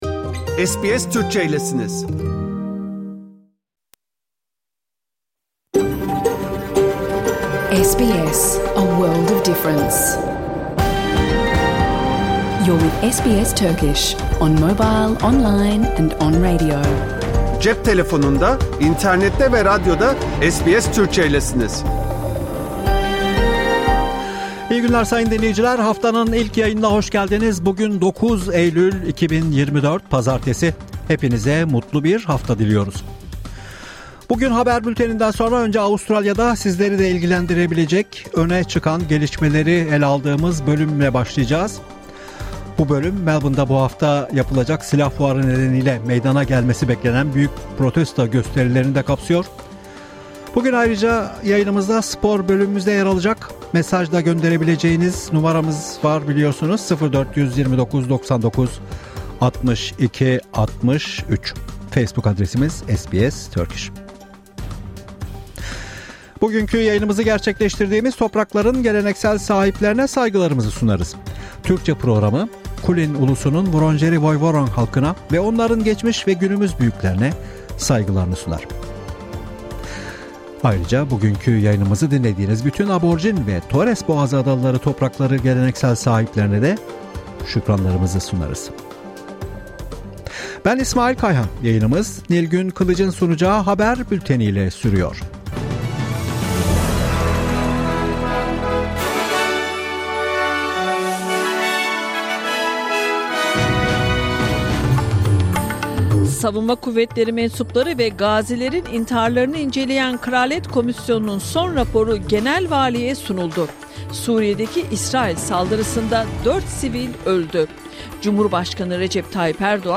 Hafta içi Salı hariç hergün her saat 14:00 ile 15:00 arasında yayınlanan SBS Türkçe radyo programını artık reklamsız, müziksiz ve kesintisiz bir şekilde dinleyebilirsiniz.